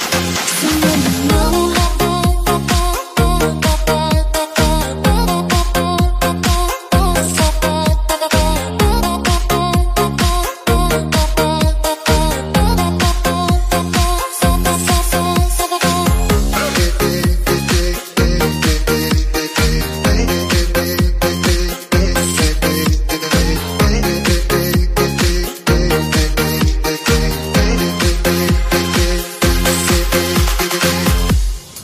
âm bass sôi động.